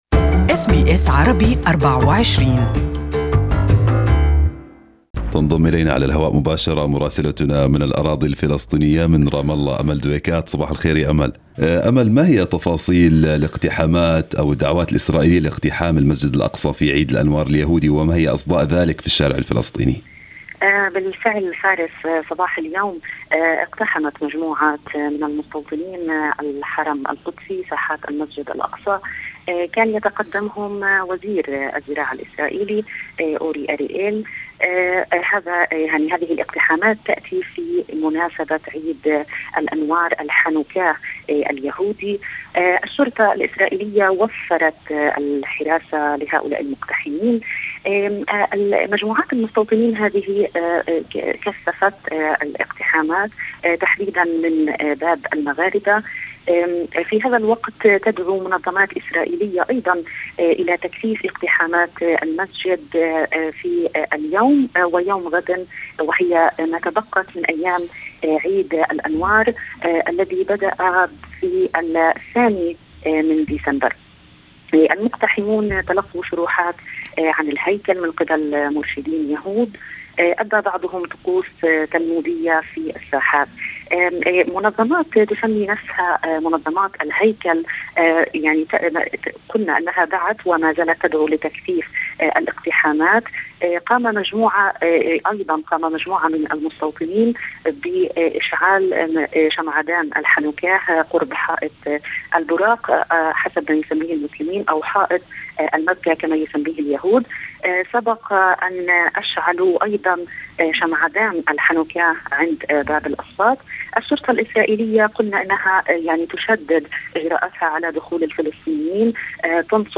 Listen to the full report from our correspondent in Arabic above